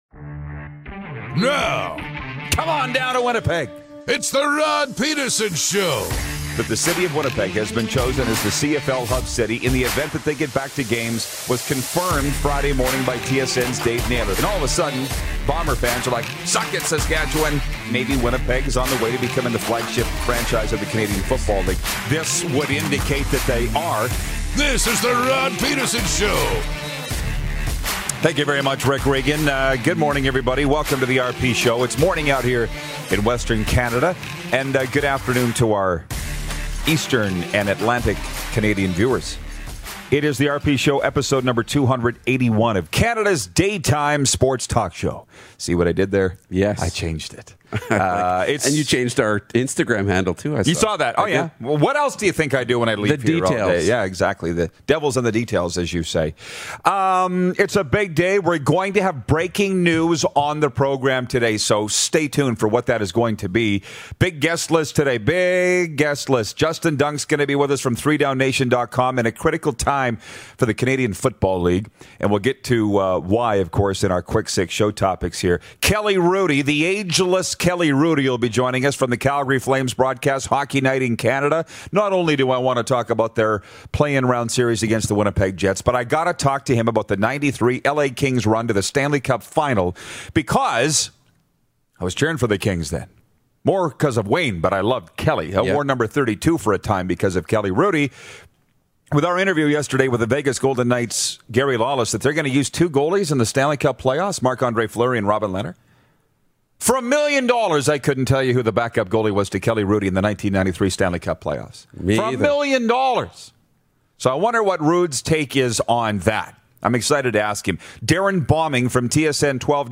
Sportsnet Analyst and long-time NHL Alum Kelly Hrudey checks in!